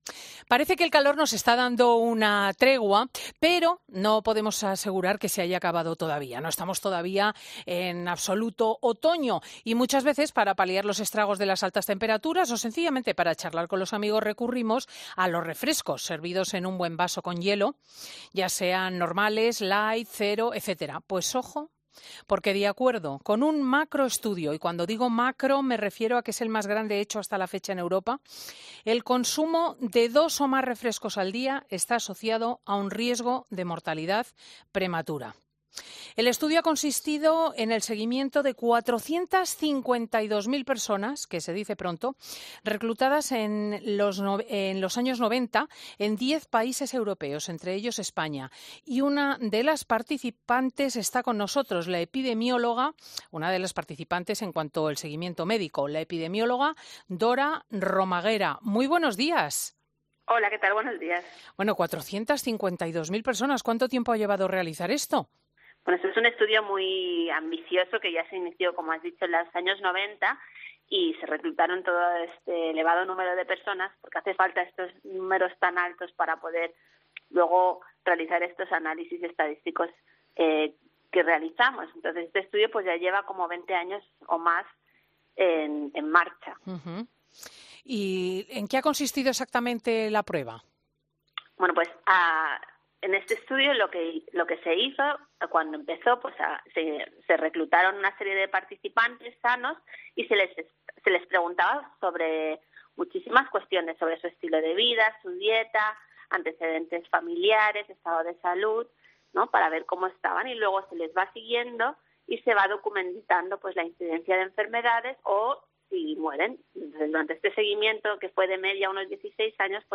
En una entrevista con Cristina López Schlichting la doctora ha manifestado que el estudio fue determinante: “Aquellos que bebían más refrescos presentaron un riesgo relativo superior de morir”, ha dicho.